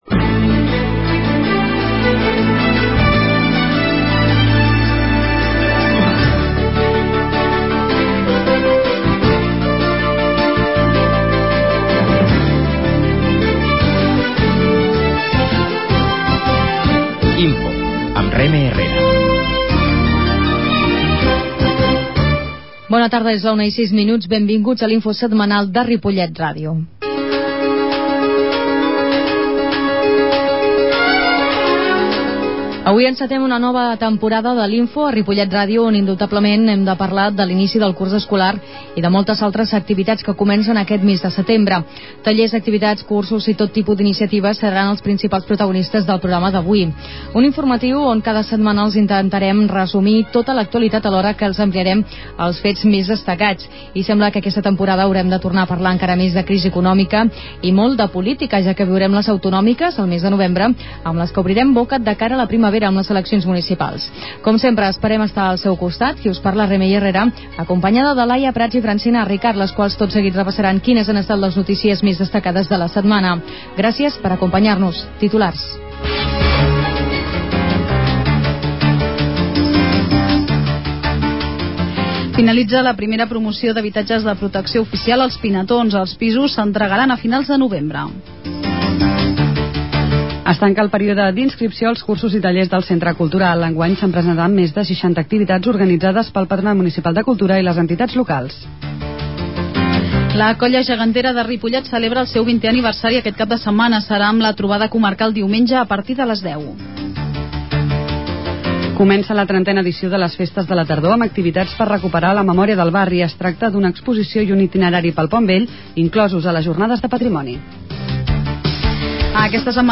La qualitat de so ha estat redu�da per tal d'agilitzar la seva baixada.